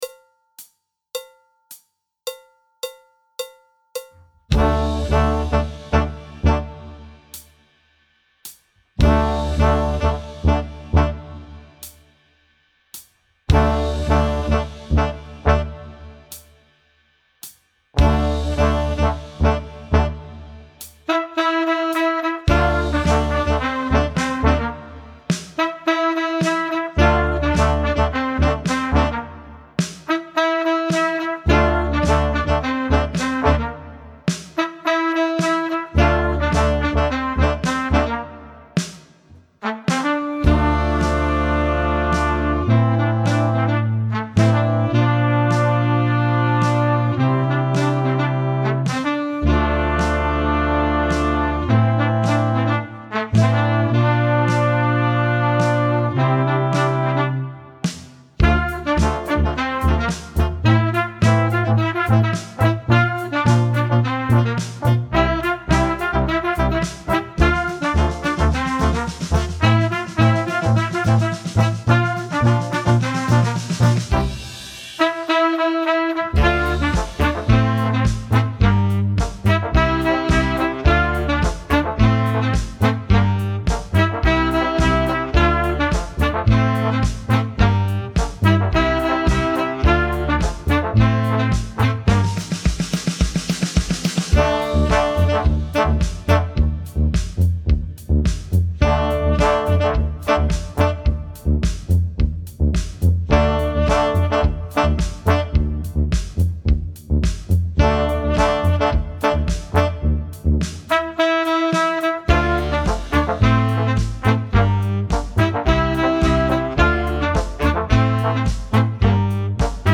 Tempo 105